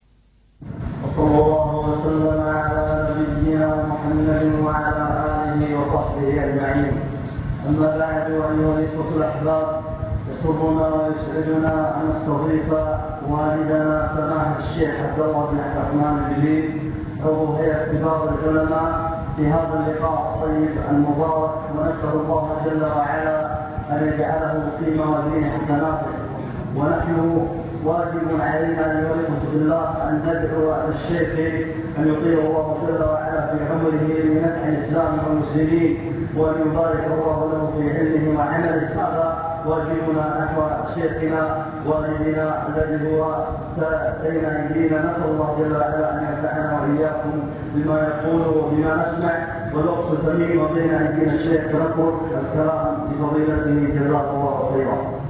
المكتبة الصوتية  تسجيلات - محاضرات ودروس  محاضرة بعنوان شكر النعم (2) نعم الله تعالى وعظمها
تقديم